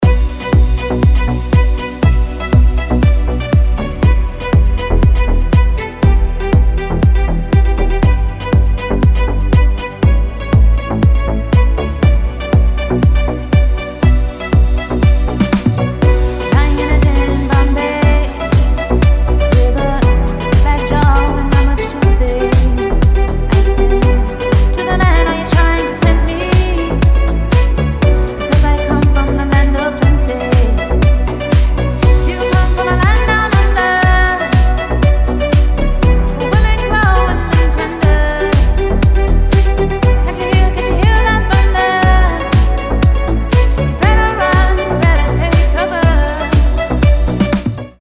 • Качество: 320, Stereo
восточные мотивы
приятные
Стиль: deep house